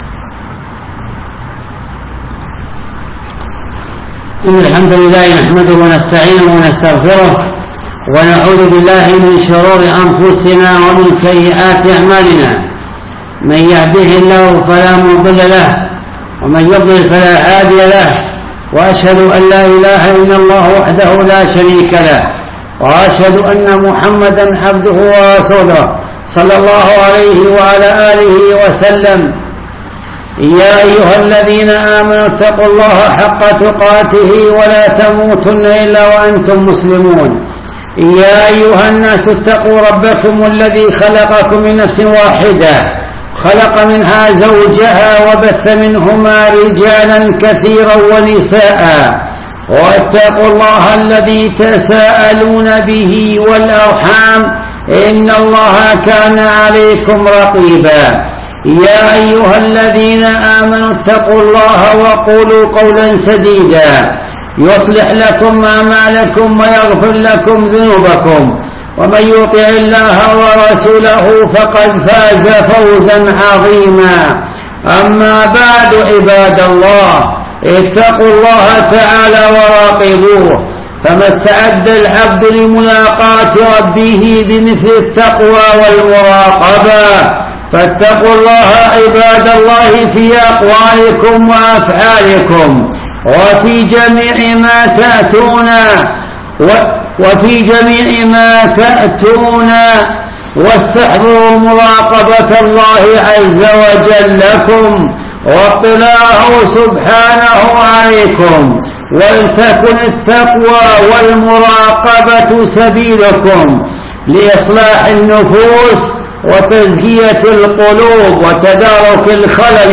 الخطب
Preferredmonthof.Shaaban.mp3